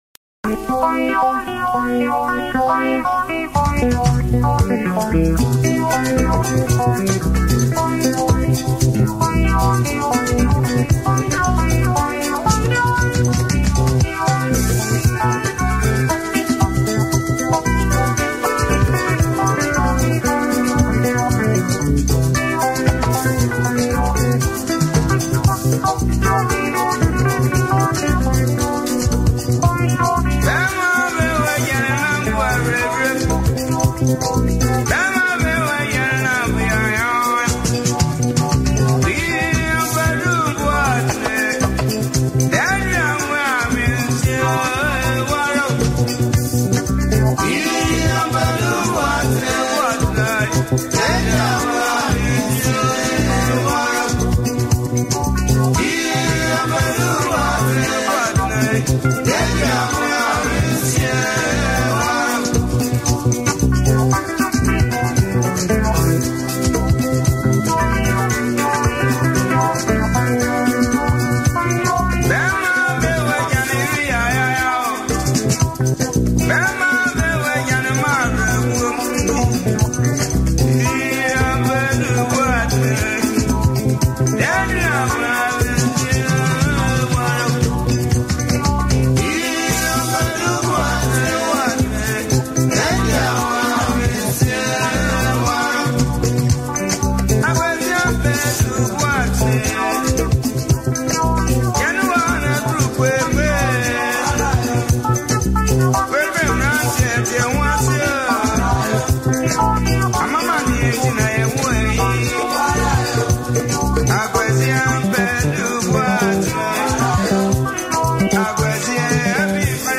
an old classical song